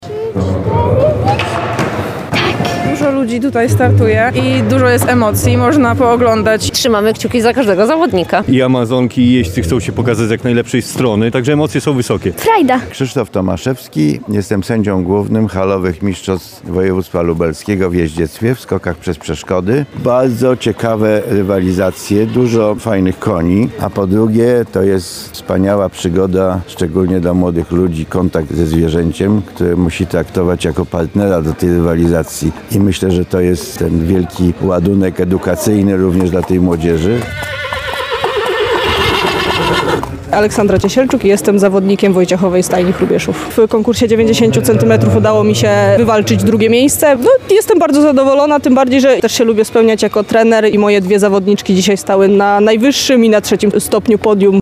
Emocje są wysokie – mówi w rozmowie z Radiem Lublin jeden z uczestników wydarzenia.
Zawody odbywają się na terenie ośrodka jeździeckiego przy ul. Doświadczalnej w Lublinie i potrwają do około godziny 18.00.